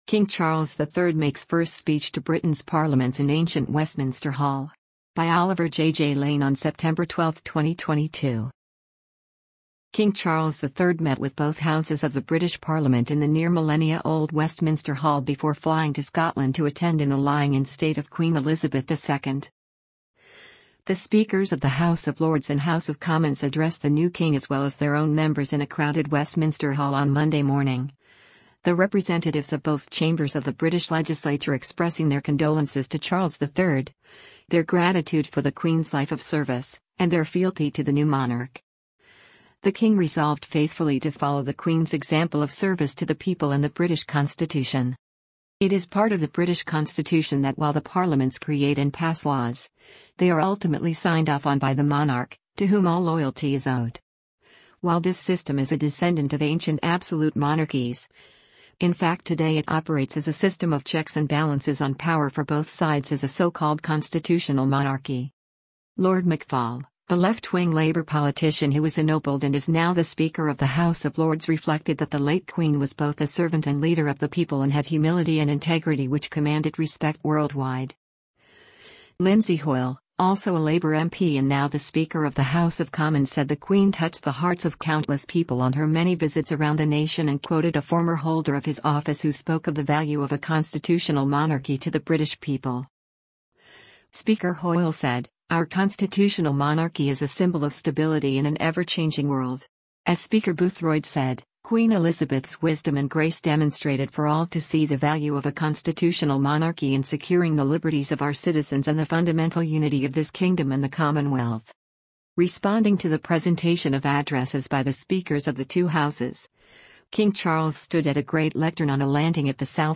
King Charles III Makes First Speech to Britain's Parliaments in Ancient Hall
Responding to the presentation of addresses by the speakers of the two houses, King Charles stood at a great lectern on a landing at the south end of Westminster Hall and delivered his own speech, expressing his gratitude for the condolences and adding his own words to the memory of the late Queen.
The King’s speech: